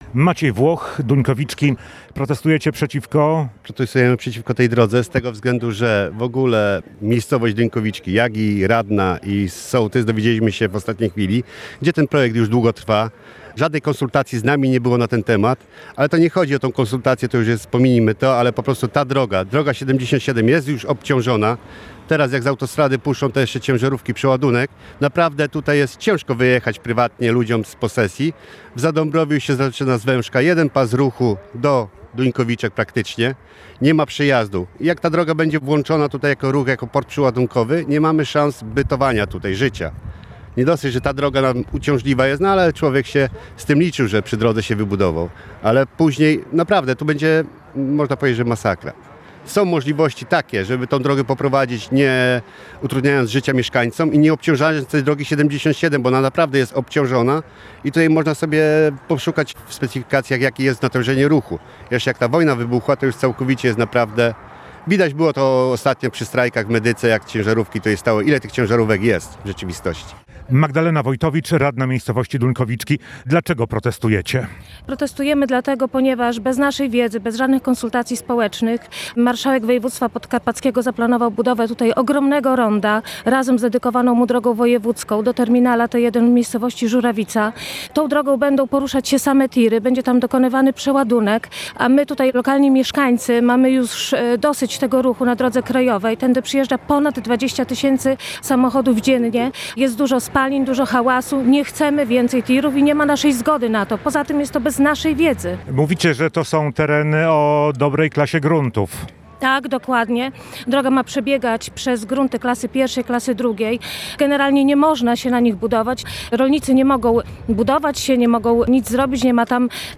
Protest rolników w Duńkowiczkach (aktualizacja)